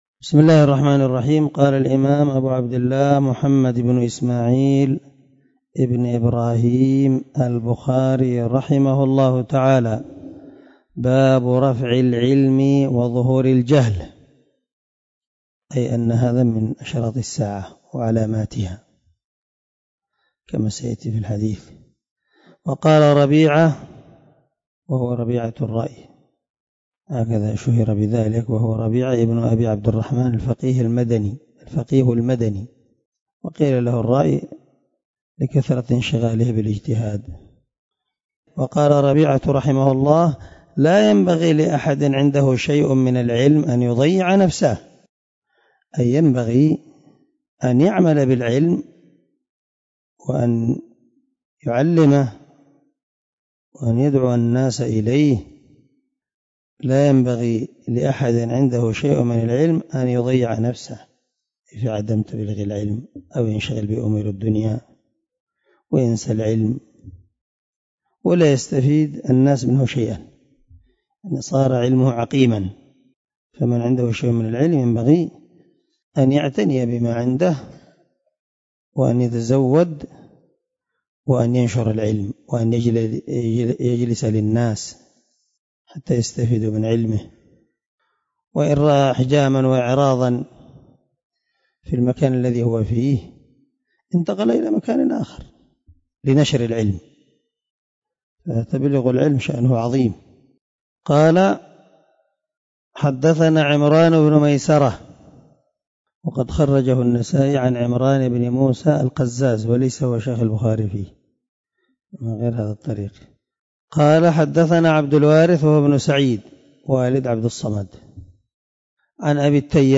079الدرس 24 من شرح كتاب العلم حديث رقم ( 80 ) من صحيح البخاري
دار الحديث- المَحاوِلة- الصبيحة.